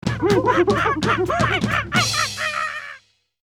MG_sfx_vine_game_finish.ogg